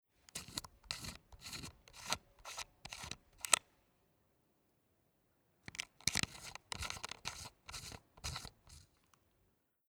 Antenne ab- und anschrauben
1316_Antenne_ab-_und_anschrauben.mp3